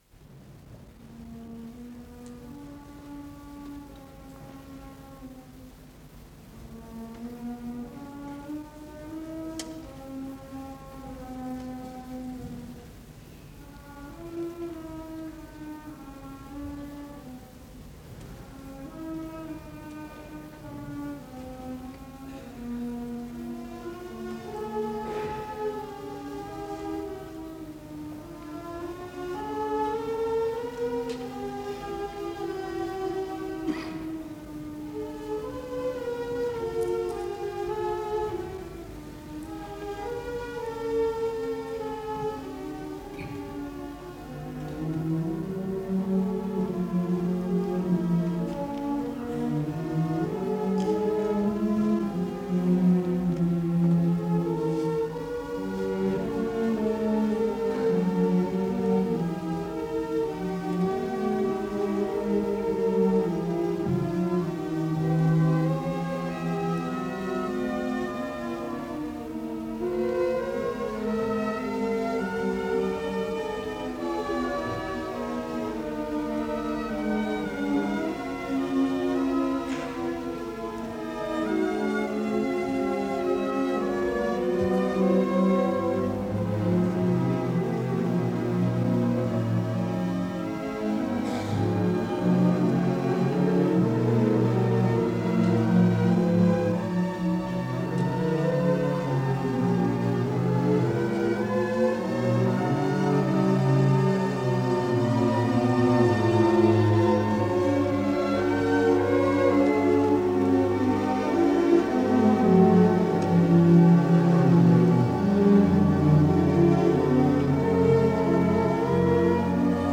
с профессиональной магнитной ленты
ИсполнителиСимфонический оркестр Ленинградской Государственной филармонии
Дирижёр - Евгений Мравинский
ВариантДубль моно